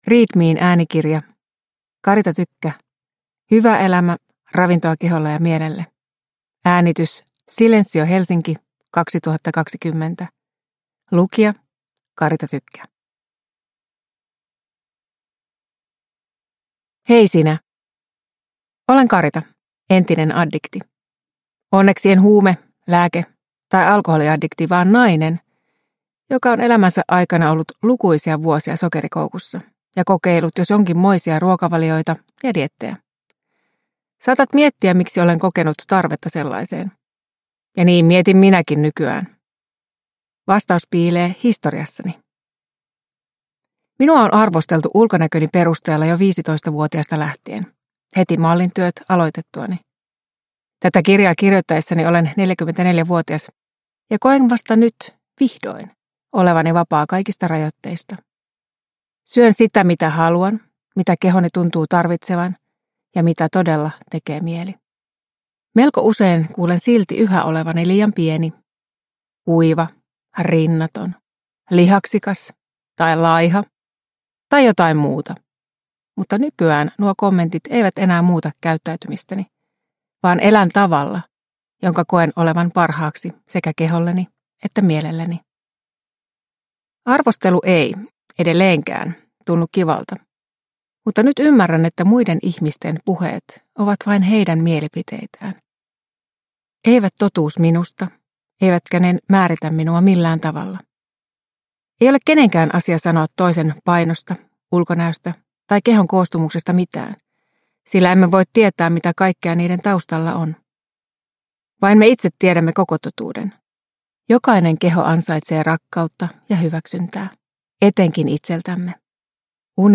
Hyvä elämä - Ravintoa keholle ja mielelle – Ljudbok